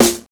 GVD_snr (7).wav